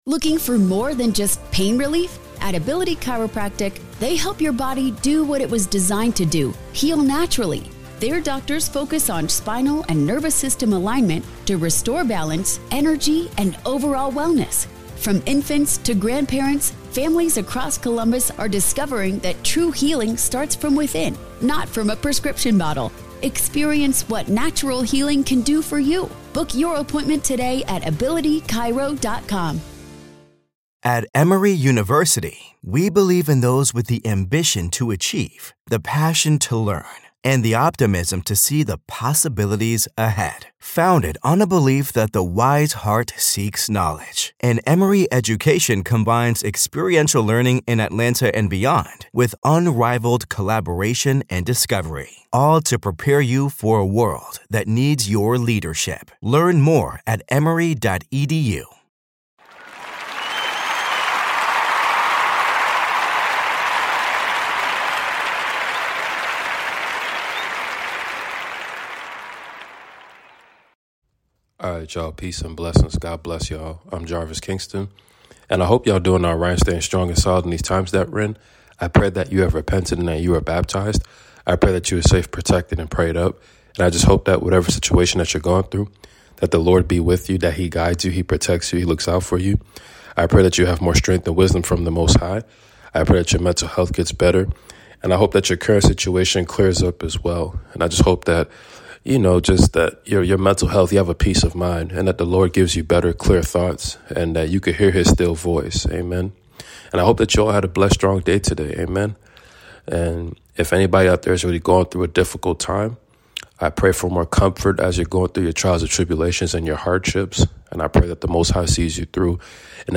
Book of Exodus reading ! Let’s be more set apart and pray !!!